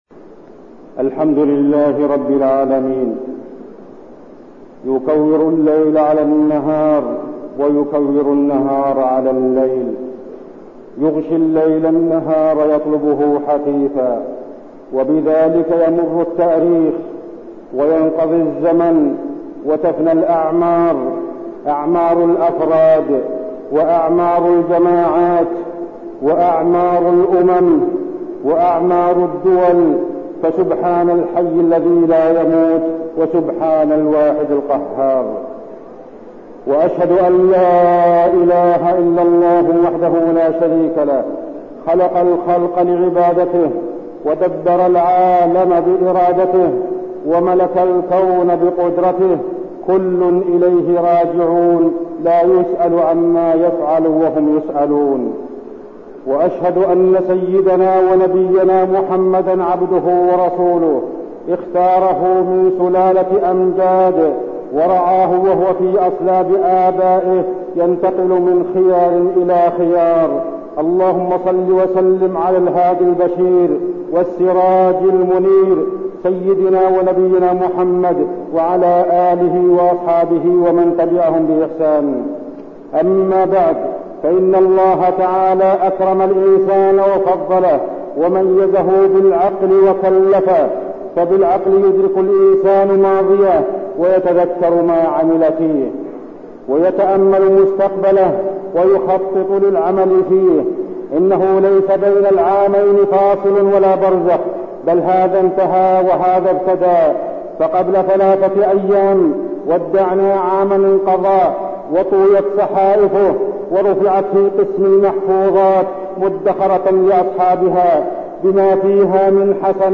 تاريخ النشر ٣ محرم ١٤١٠ هـ المكان: المسجد النبوي الشيخ